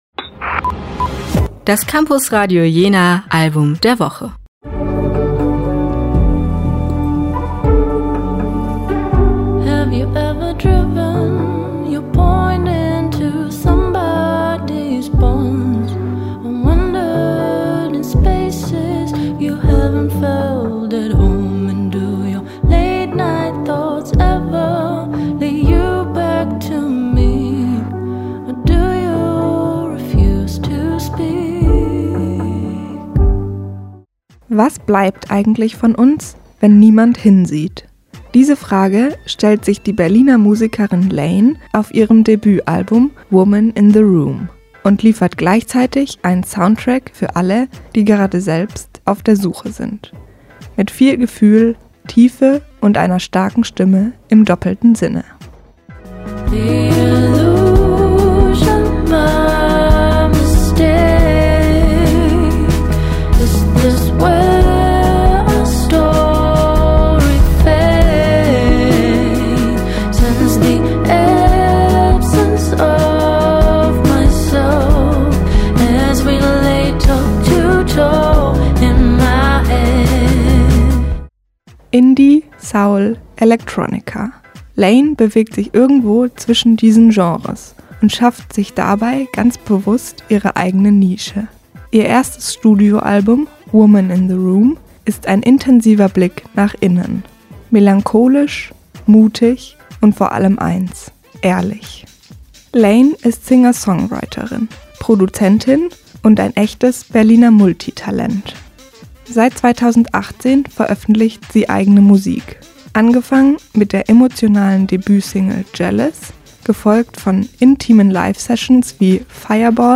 Indie, Soul, Electronica
Zwischen sanften Beats, introspektiven Lyrics und subtilen elektronischen Elementen entsteht ein Sound, der nachwirkt – wie ein Gespräch mit sich selbst, das man nicht so schnell vergisst.